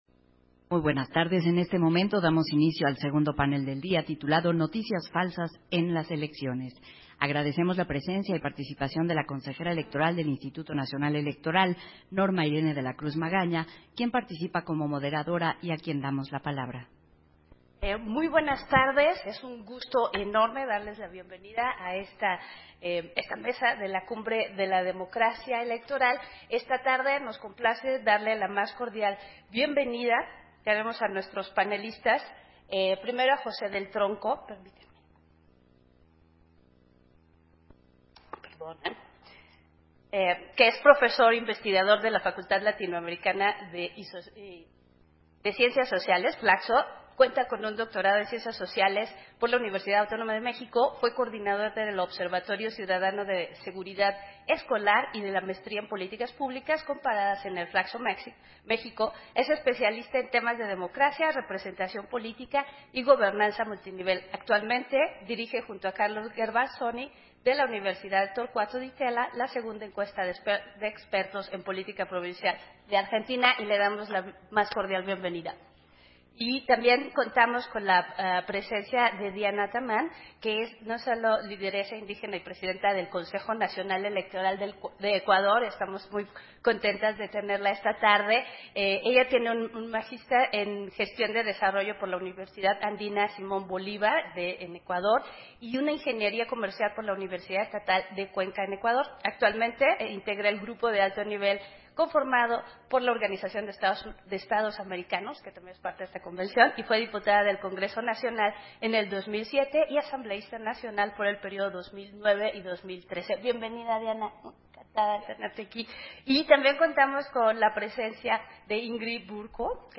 Versión estenográfica del panel, Noticias falsas en las elecciones, en el marco de la II Cumbre de la Democracia Electoral